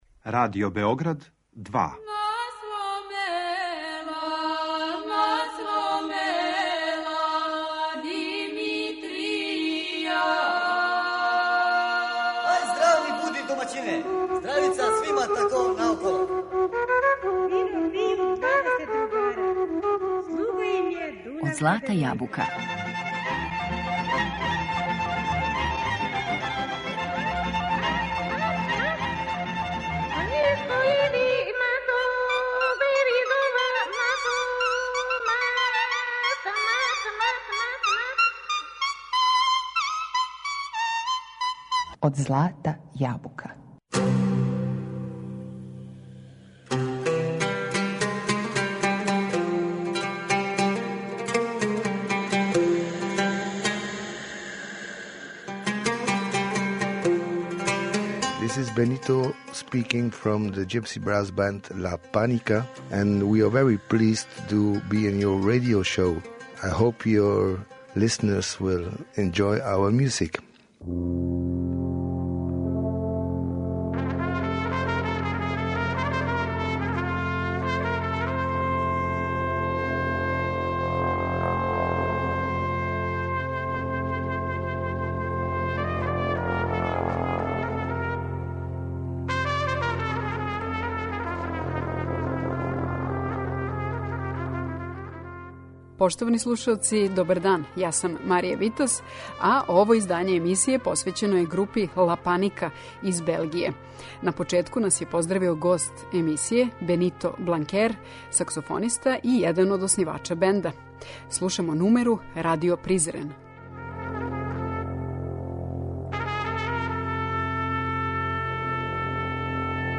После дуже паузе, на сцену се вратио белгијски састав La Panika, посвећен балканској, ромској, оријенталној музици.